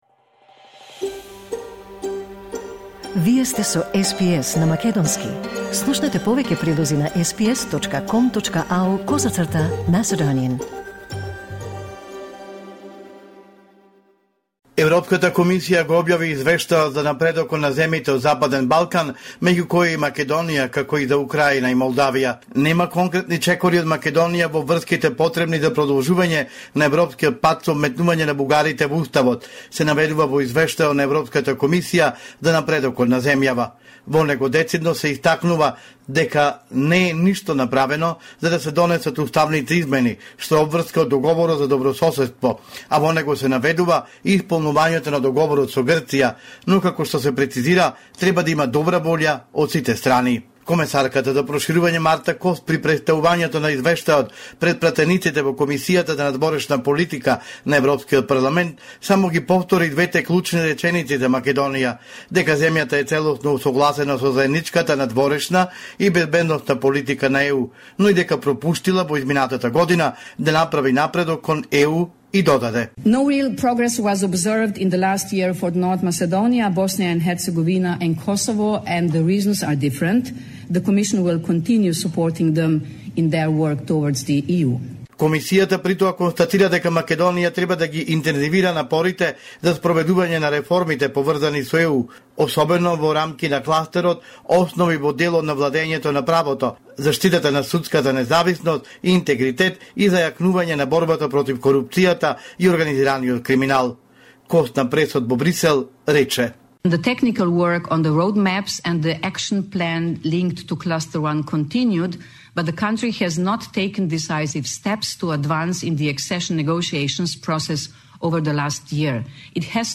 Извештај од Македонија 5 ноември 2025